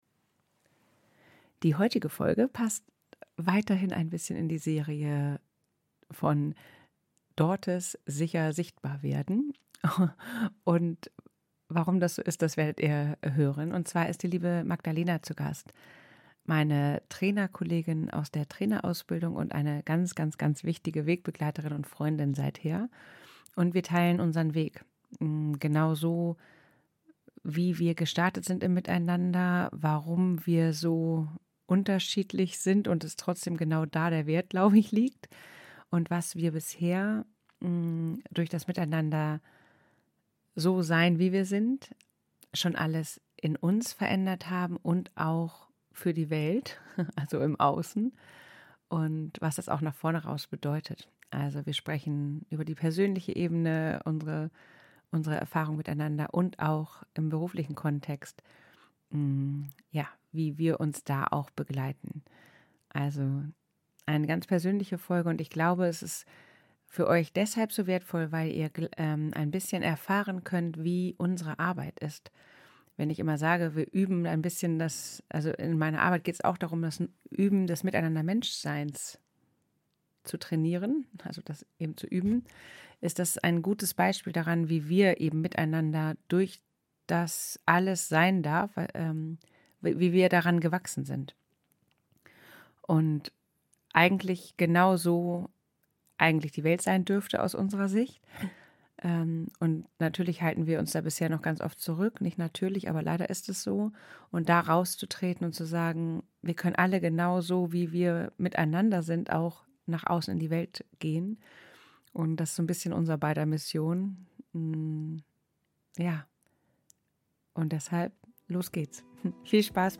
Dieses Sofa-Gespräch ist eine Einladung, Freundschaft und auch Zusammenarbeit neu zu denken: als einen Raum, in dem Ehrlichkeit verbindet, Unterschiedlichkeit ergänzt und unsere jeweilige Energie spürbar wird – für uns selbst und für die Menschen, mit denen wir leben und arbeiten.